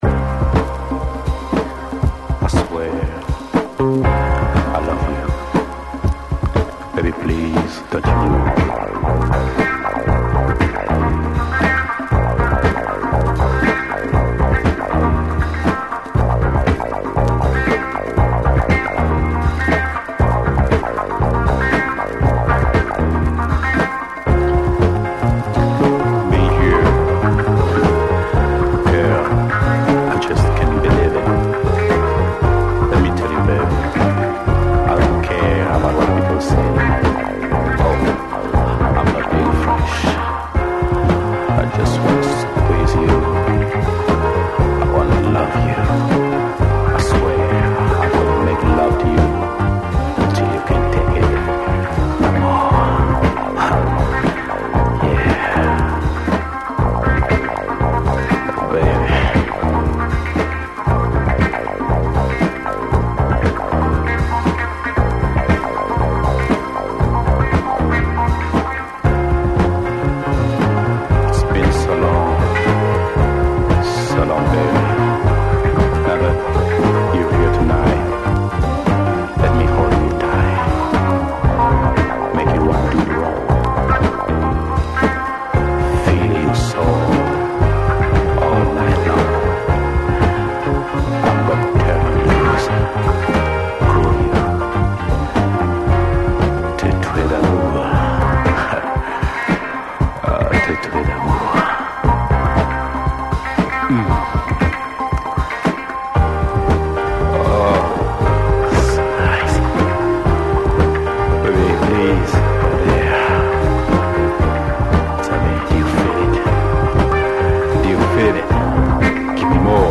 ※傷はありませんがプレスに起因するチリノイズあり。
※この盤からの録音ですので「試聴ファイル」にてご確認下さい。